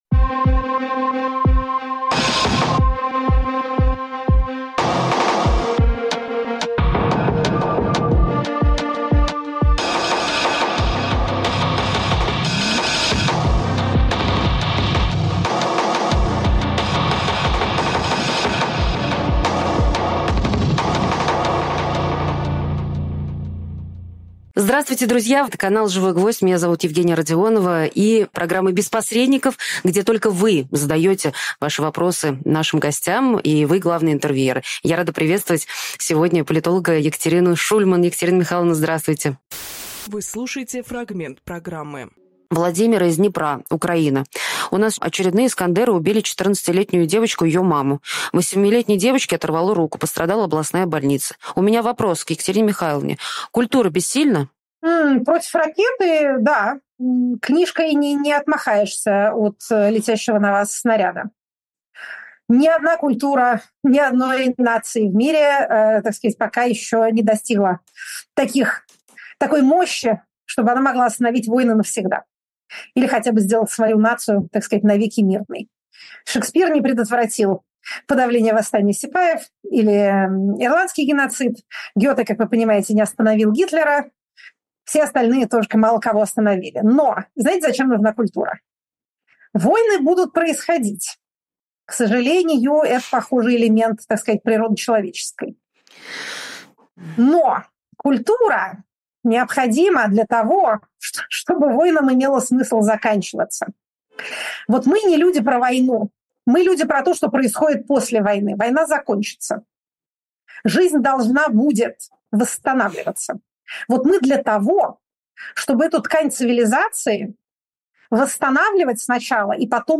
Фрагмент эфира от 26.10.24